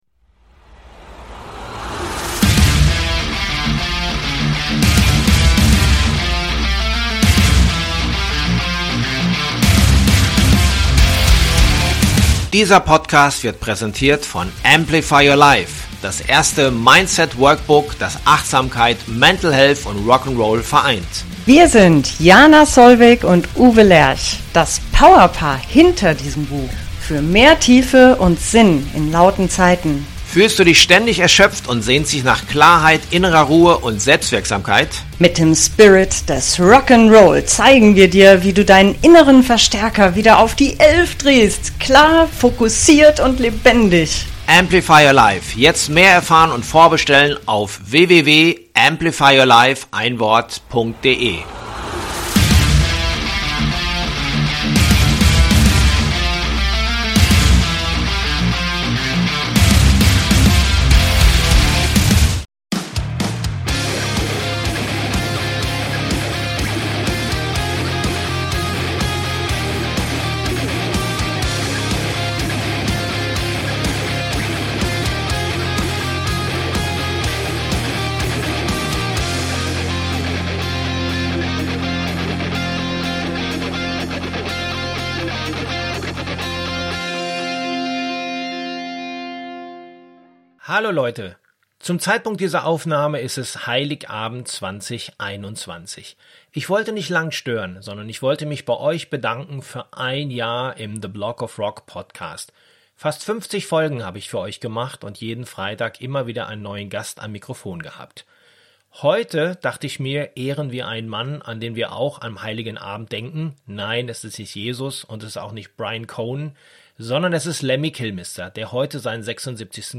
Wir erinnern heute an den großartigen Musiker und Menschen - und dazu habe ich für Euch als Weihnachtsgeschenk ein Gespräch mit DORO PESCH über ihren Kumpel.